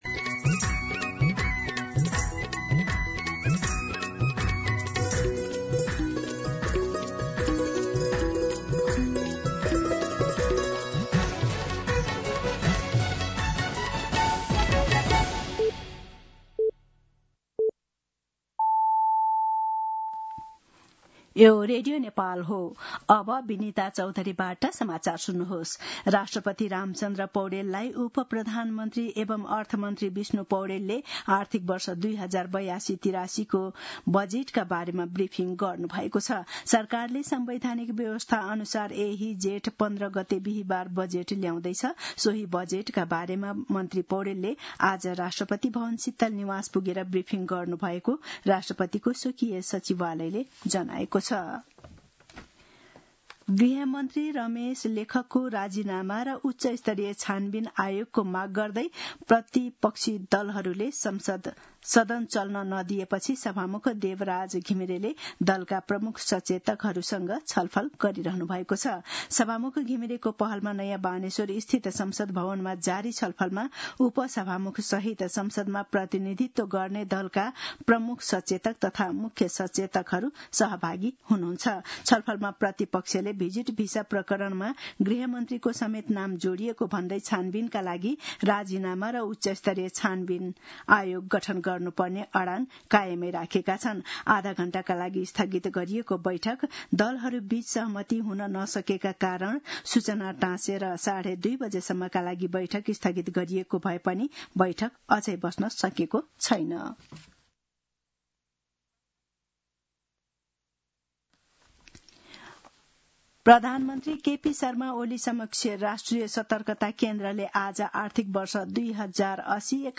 दिउँसो ४ बजेको नेपाली समाचार : १३ जेठ , २०८२
4pm-News-13.mp3